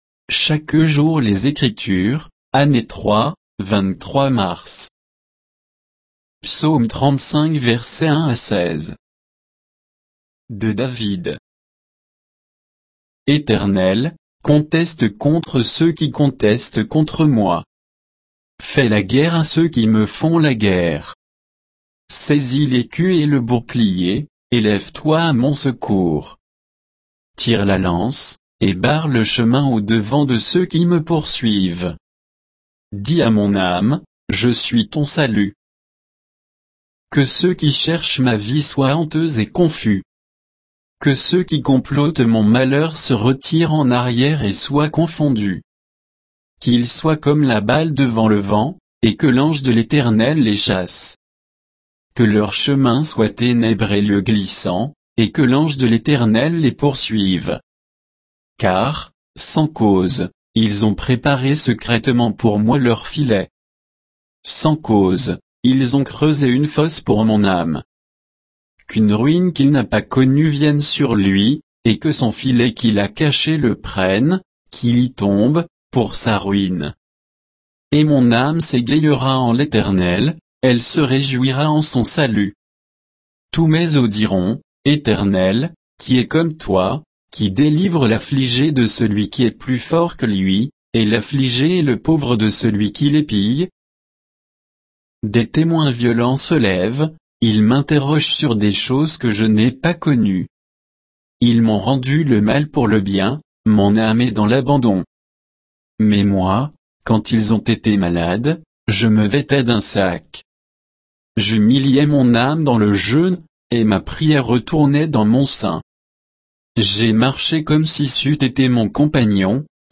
Méditation quoditienne de Chaque jour les Écritures sur Psaume 35, 1 à 16